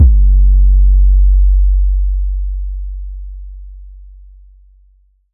Waka 808 - 2 (5).wav